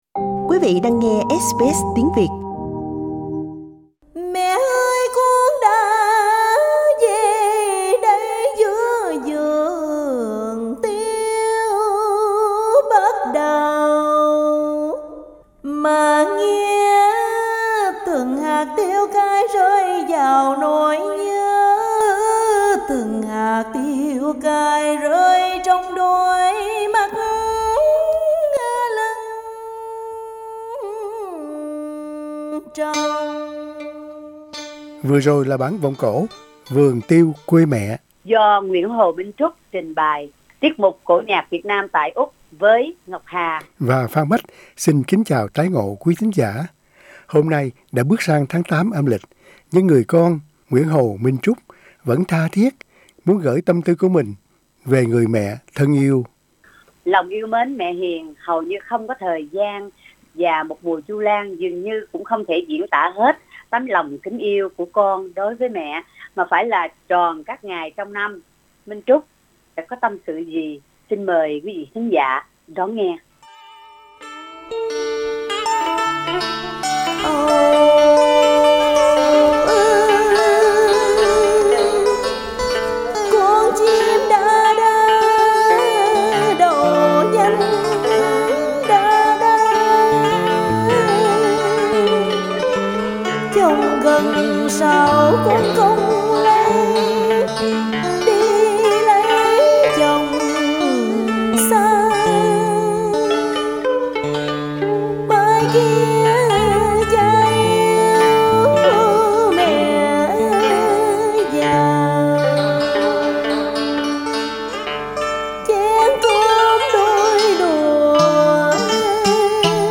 Cổ nhạc Việt Nam tại Úc